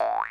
Sound clip of Spring Mario bouncing in Super Mario Galaxy
SMG_Spring_Bound.wav